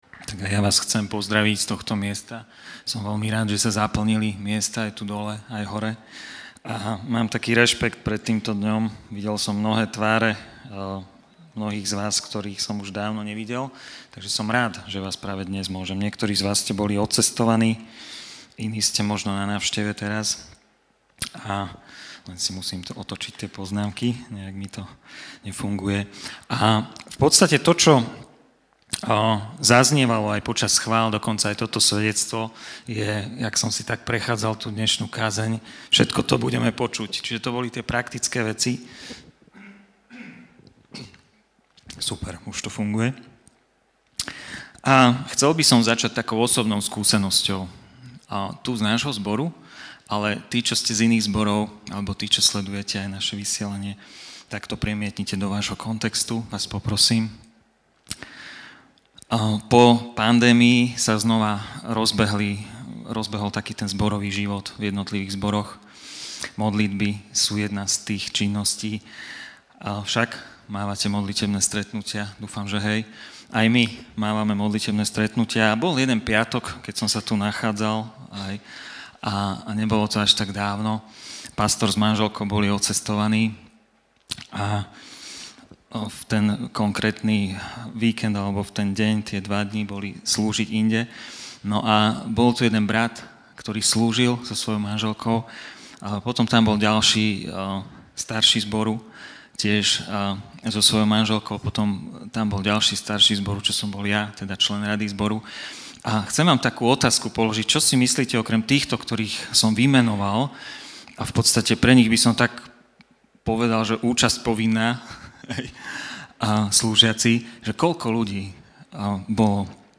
Vypočujte si kázne z našich Bohoslužieb